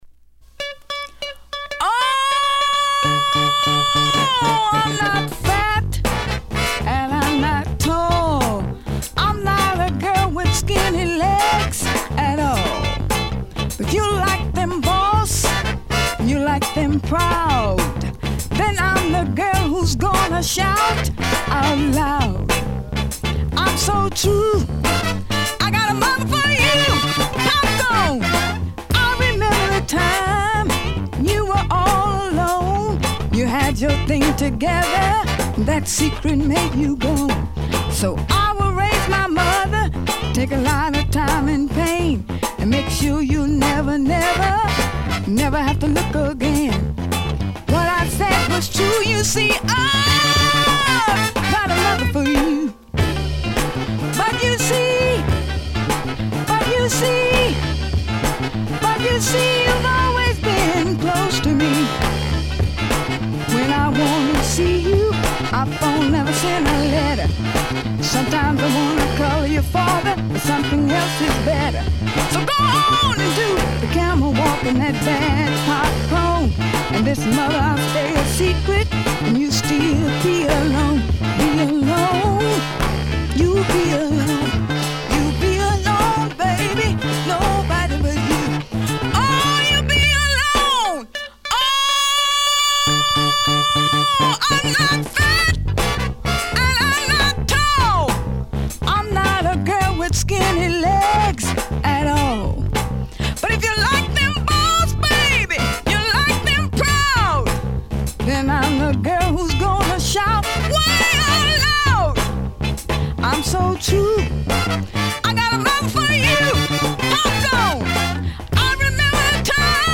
Funk/Soul 女性ファンク・ソウルシンガー
両面共再生良好です。
Side A 試聴はここをクリック ※実物の試聴音源を再生状態の目安にお役立てください。